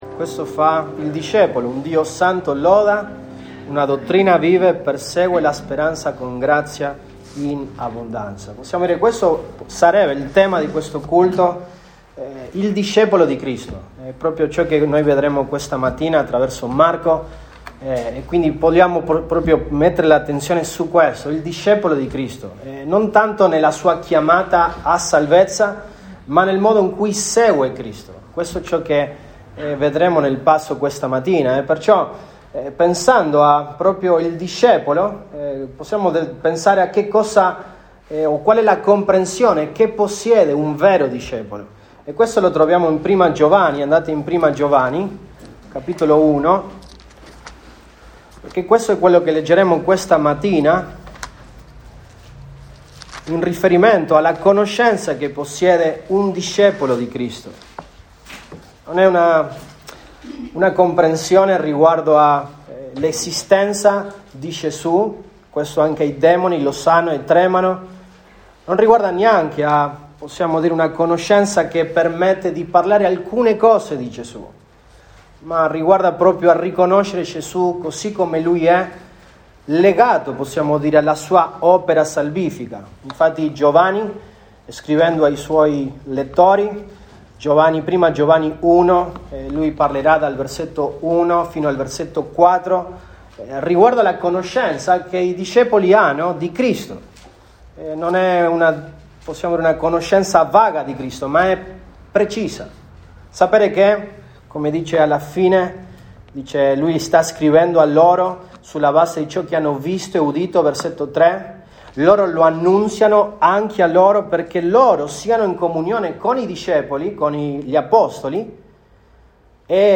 Set 25, 2022 I discepoli di Gesù Cristo MP3 Note Sermoni in questa serie I discepoli di Gesù Cristo.